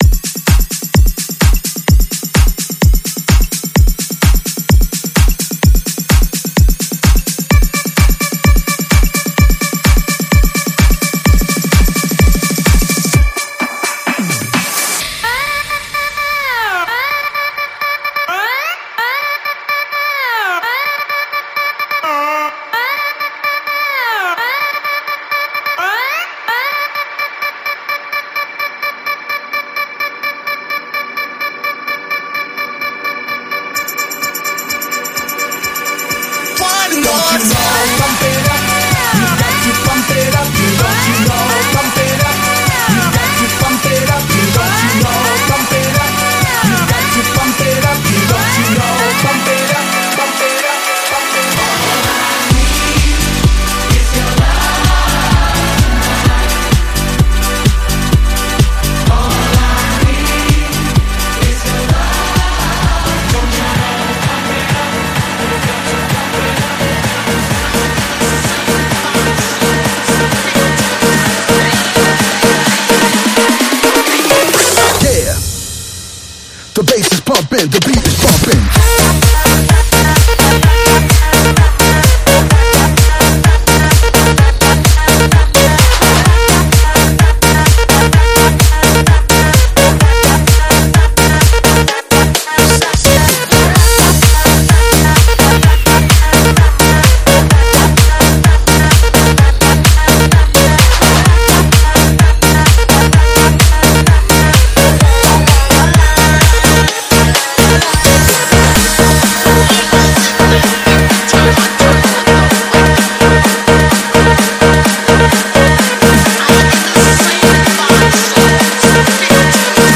试听文件为低音质，下载后为无水印高音质文件